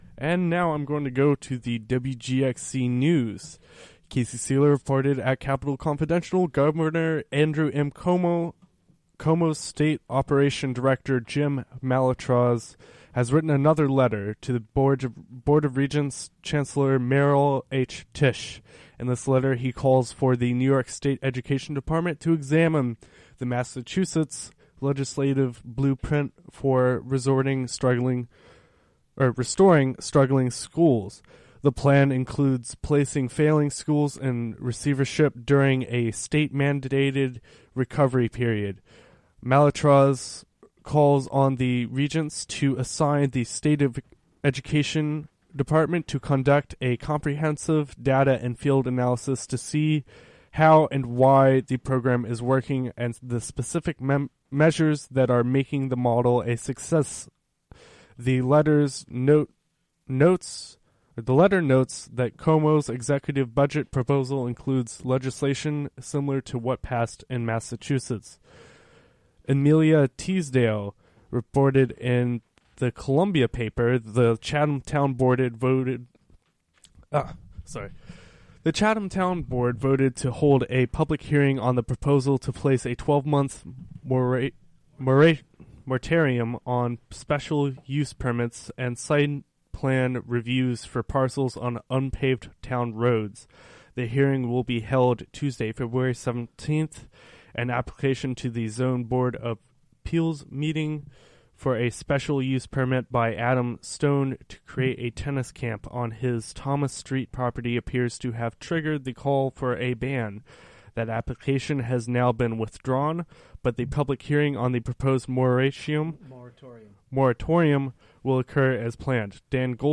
(5:55) Local news and weather for Monday, February 16, 2015.